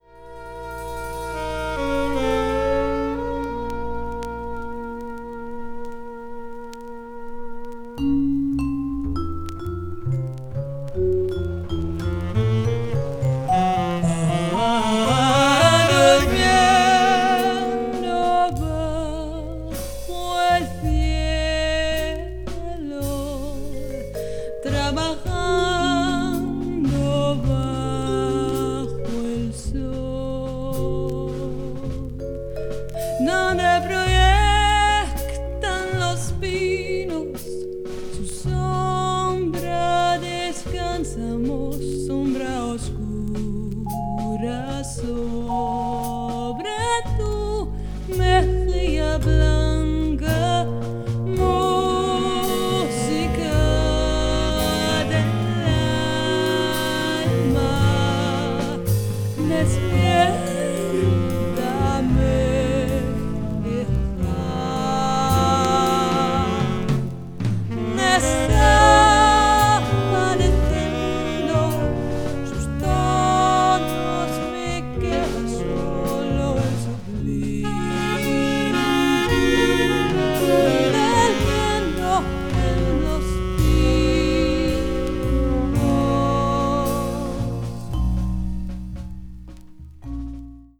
avant-jazz   contemporary jazz   free jazz   post bop